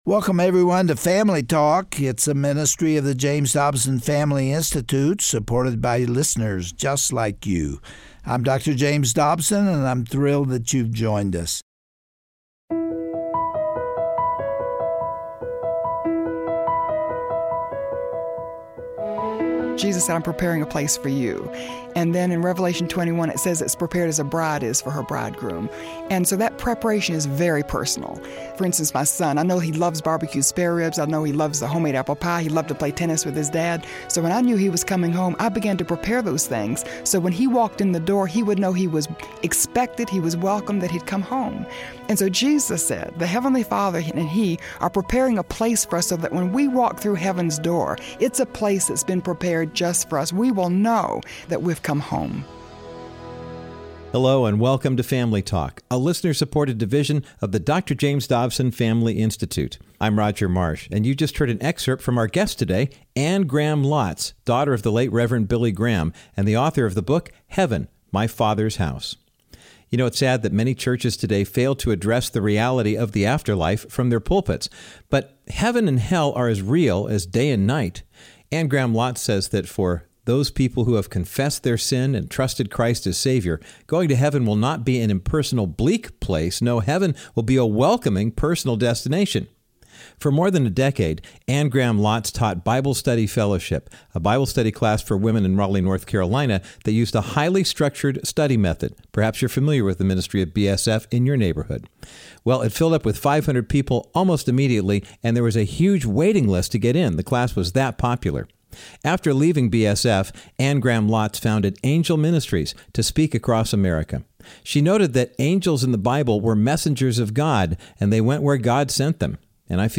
On today’s edition of Family Talk, Dr. James Dobson concludes his discussion with Anne Graham Lotz about her book on Heaven for adults entitled, Heaven: My Father's House. Anne shares that for those who have confessed their sin and trusted Christ as their Savior, Heaven is the ultimate promise fulfilled.